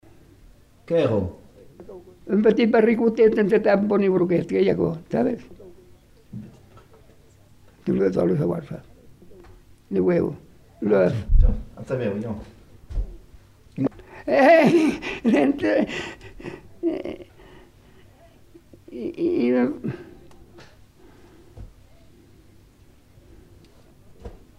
Lieu : Pompiac
Effectif : 1
Type de voix : voix d'homme
Production du son : récité
Classification : devinette-énigme